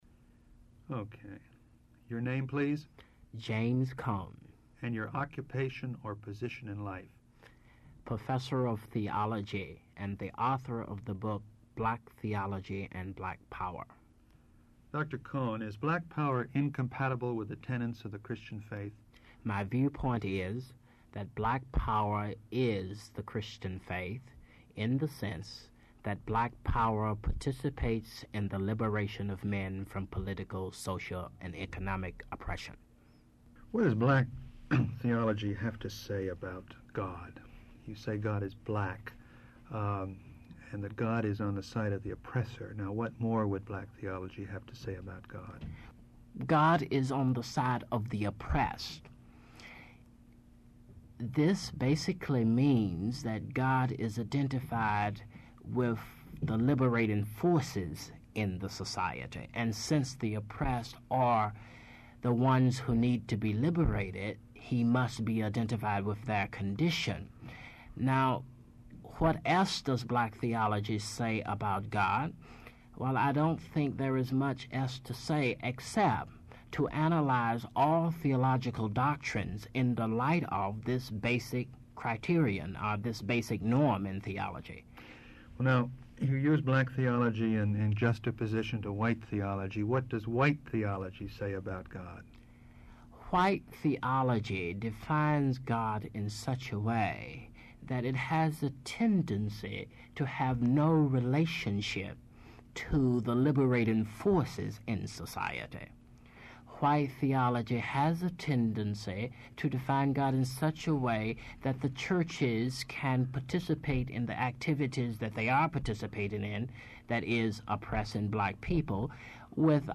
Dr. James Cone, Professor of Theology and author of “Black Theology and Black Power”, explains the fundamental liberating message of “black theology” and the importance of an equality of power before reconciliation is possible.